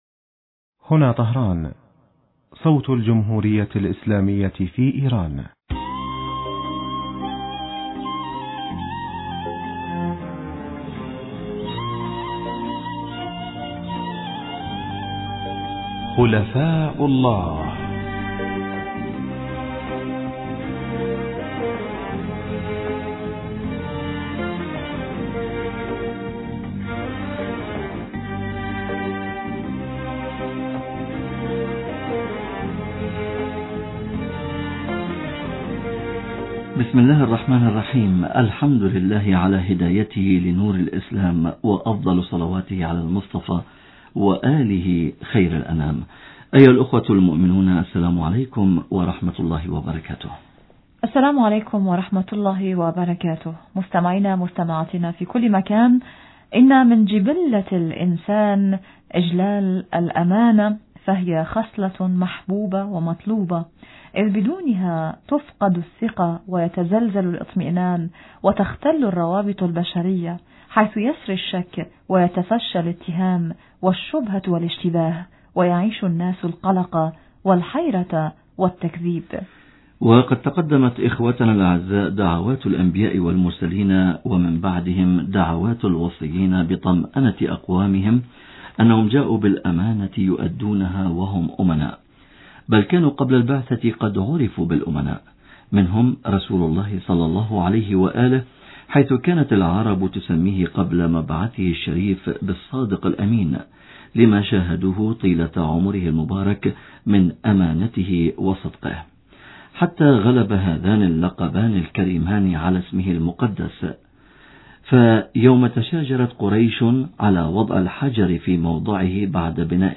في الاتصال الهاتفي التالي: